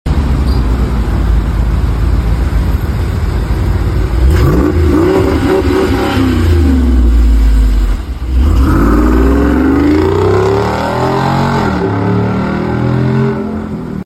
6.4L V8 470 Horsepower